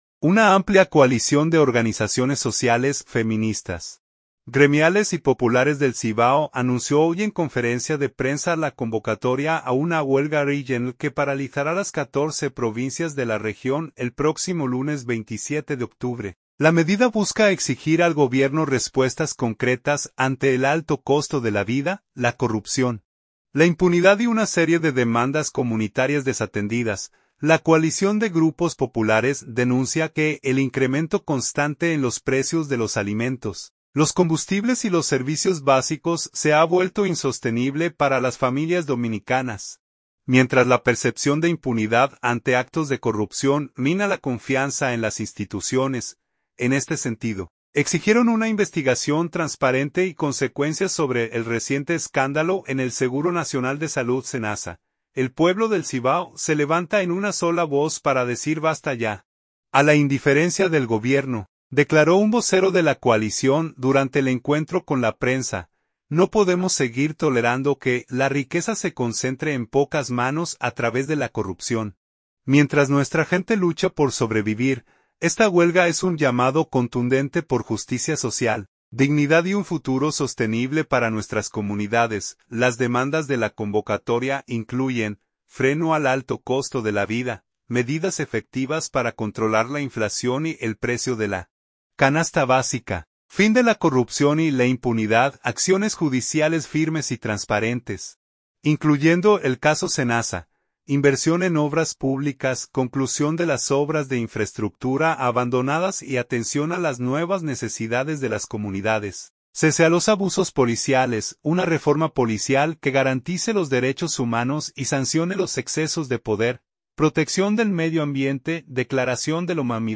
SANTIAGO, REP. DOMINICANA.– Una amplia coalición de organizaciones sociales, feministas, gremiales y populares del Cibao anunció hoy en conferencia de prensa la convocatoria a una huelga regional que paralizará las 14 provincias de la región el próximo lunes 27 de octubre.
"El pueblo del Cibao se levanta en una sola voz para decir ¡basta ya! a la indiferencia del gobierno", declaró un vocero de la coalición durante el encuentro con la prensa.